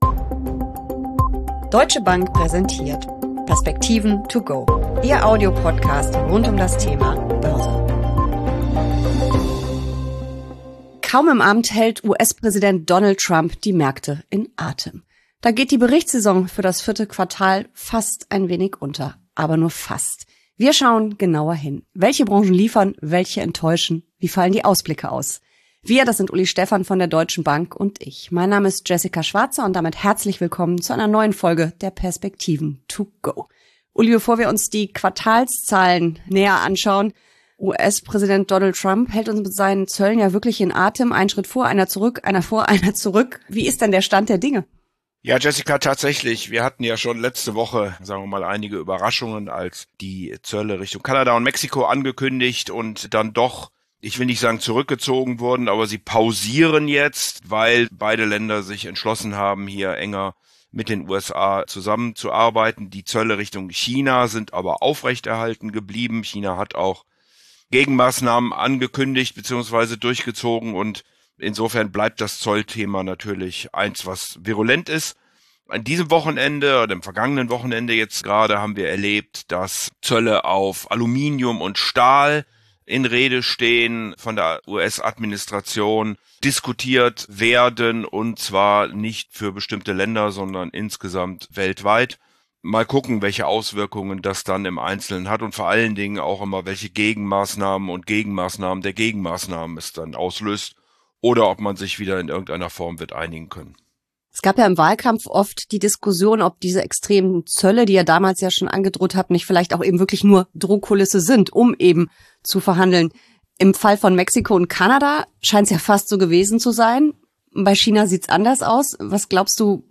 Schnell, pragmatisch und auf den Punkt.